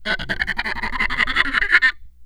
ape.wav